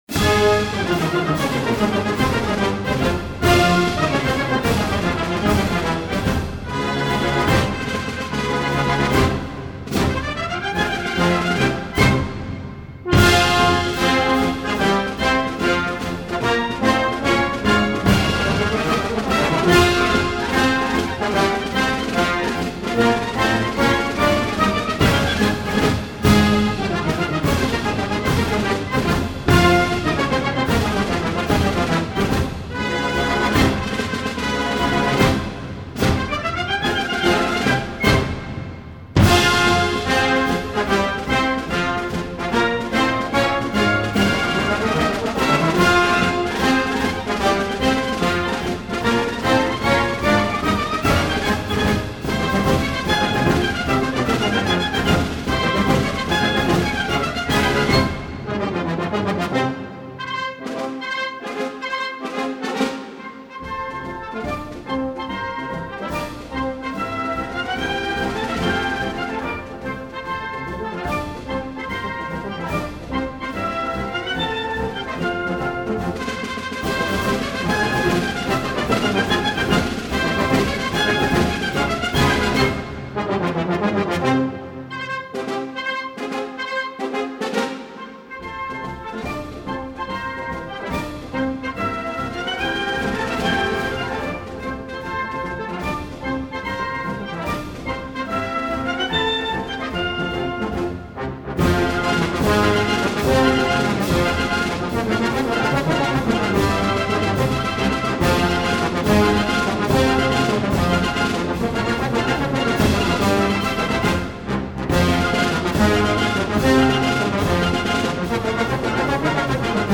Band → Concert Marches